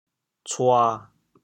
娶 部首拼音 部首 女 总笔划 11 部外笔划 8 普通话 qǔ 潮州发音 潮州 cua7 cu2 文 潮阳 cua7 cu2 澄海 cua7 cu2 揭阳 cua7 cu2 饶平 cua7 cu2 汕头 cua7 cu2 中文解释 娶〈动〉 (会意兼形声。